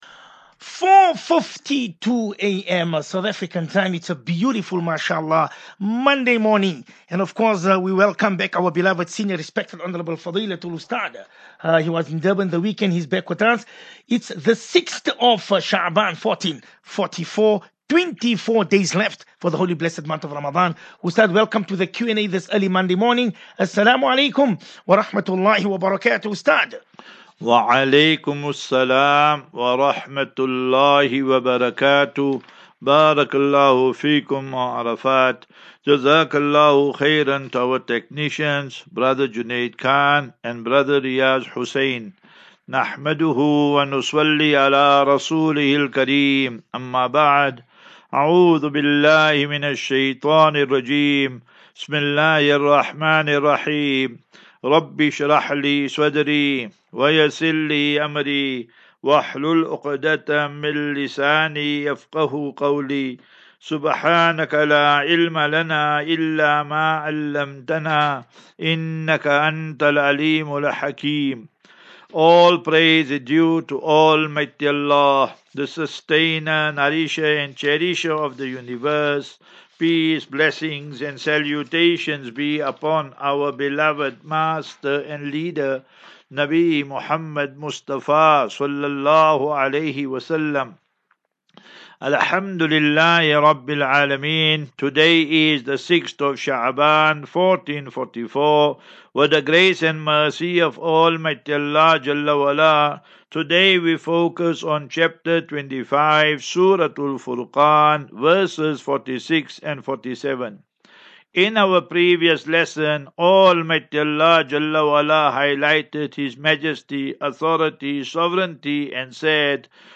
View Promo Continue Install As Safinatu Ilal Jannah Naseeha and Q and A 27 Feb 27 Feb 23 Asafinatu Illal Jannah 36 MIN Download